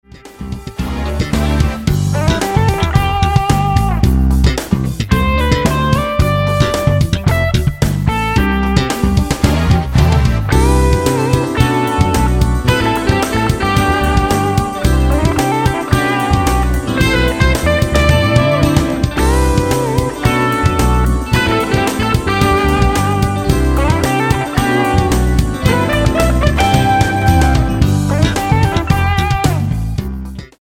saxophones
guitars, keyboards and harmonicas
world-beat rhythms and sophisticated jazz arrangements
coastal smooth sound